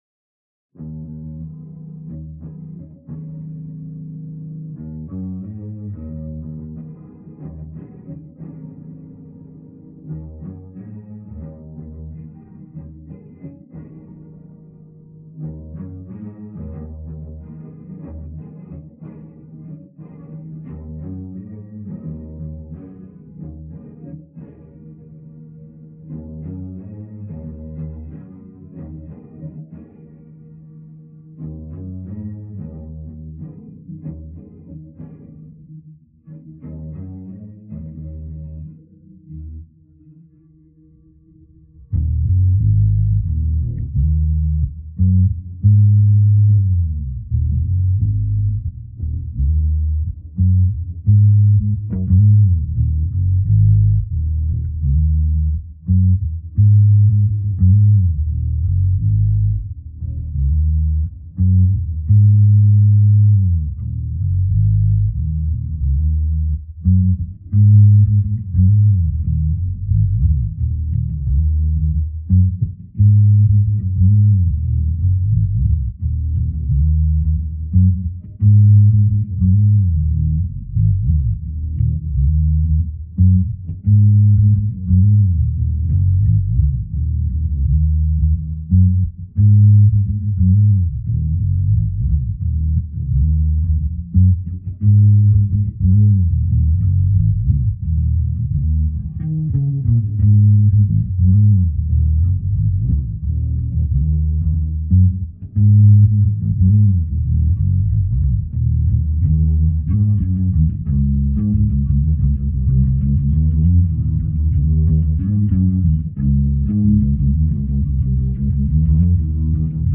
Here’s the isolated bass track for